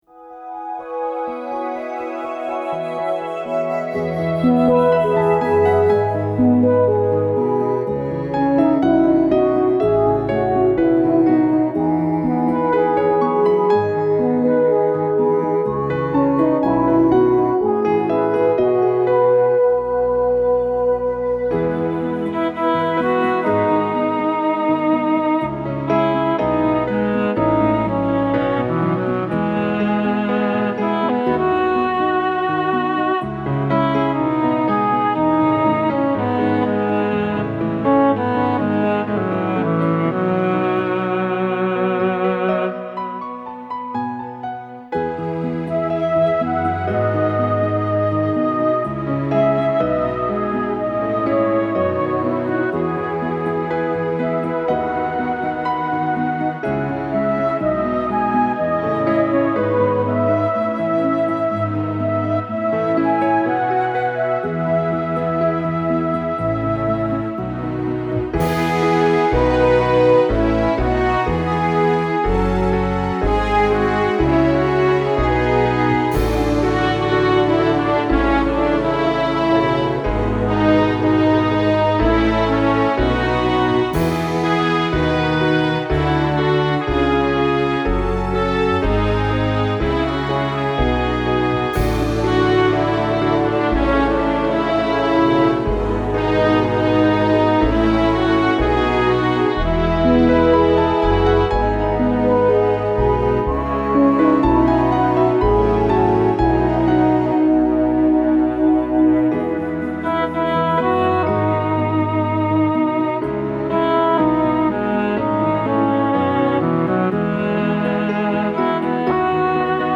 Let these instrumentals take you on a musical journey of healing with their touching melodies.
Later on, I gravitated more to acoustic guitar, piano and piano, and solo piano.